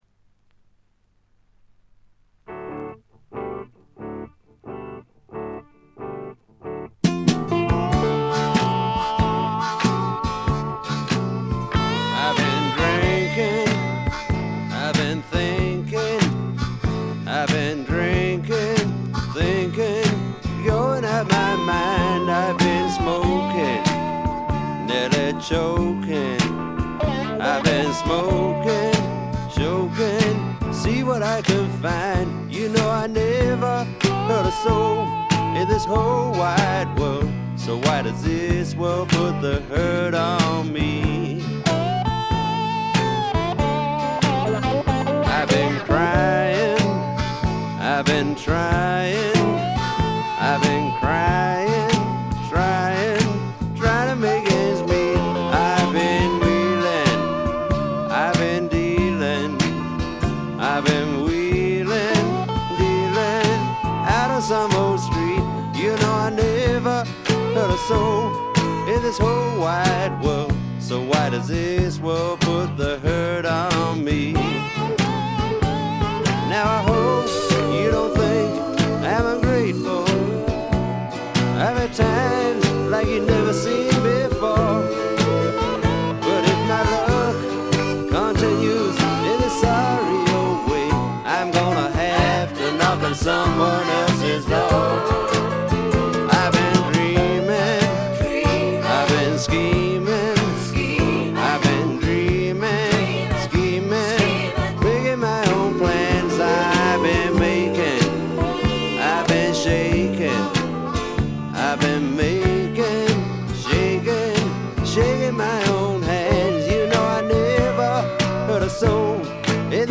ほとんどノイズ感無し。
ずばり英国スワンプの名作です！
多少枯れぎみの哀愁を帯びた声で、ちょっと投げやりな歌い方もサウンドの雰囲気にマッチしています。
試聴曲は現品からの取り込み音源です。